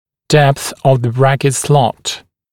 [depθ əv ðə ‘brækɪt slɔt][дэпс ов зэ ‘брэкит слот]глубина паза брекета